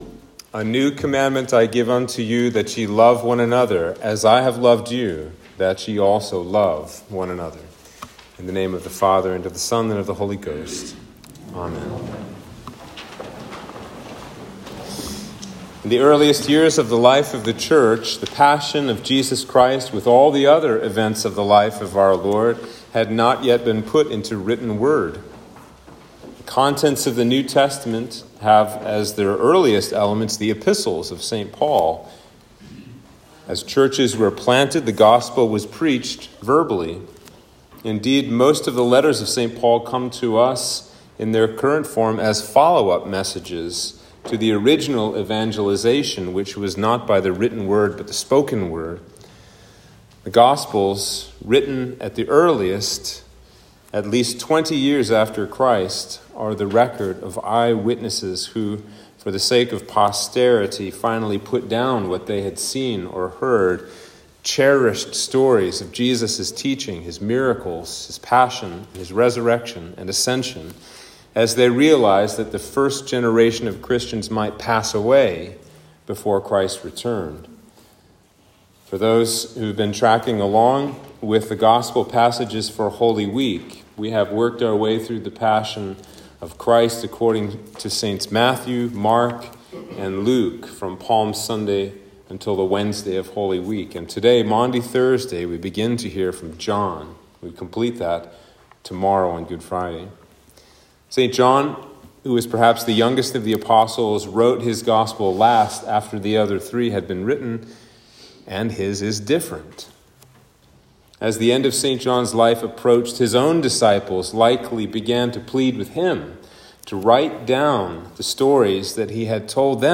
Sermon for Maundy Thursday